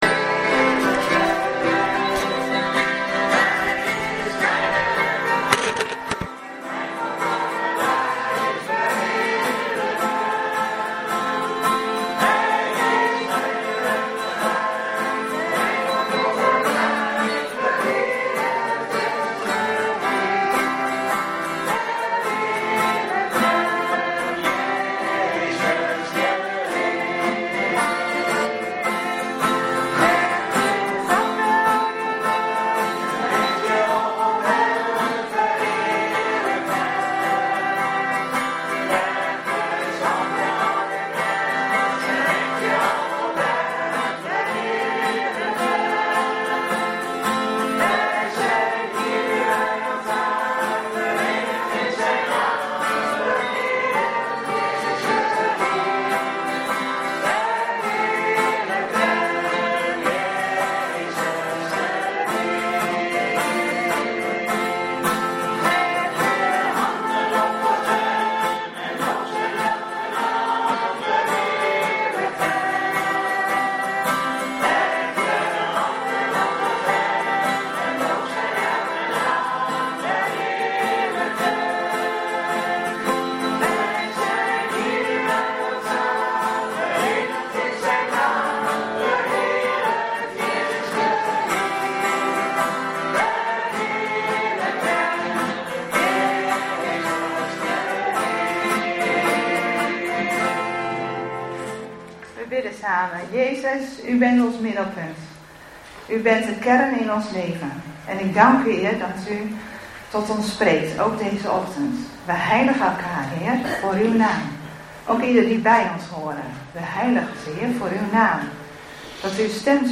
23 november 2025 dienst - Volle Evangelie Gemeente
Preek